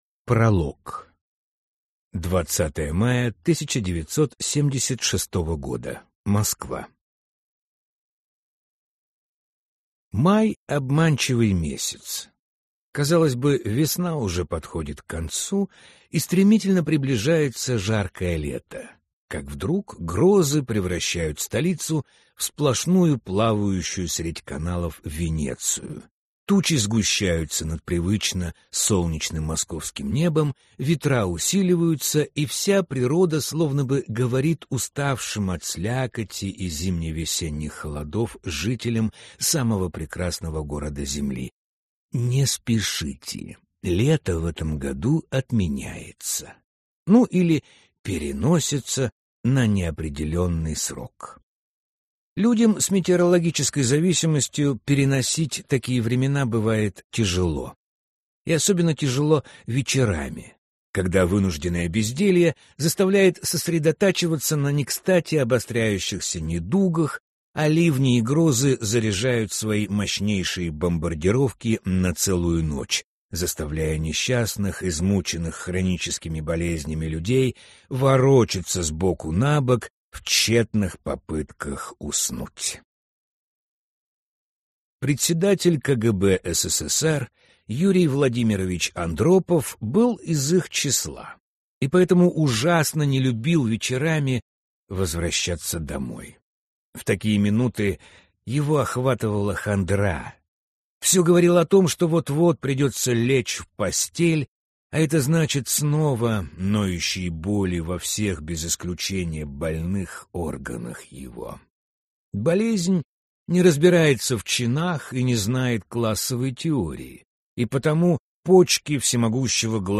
Аудиокнига КГБ против СССР | Библиотека аудиокниг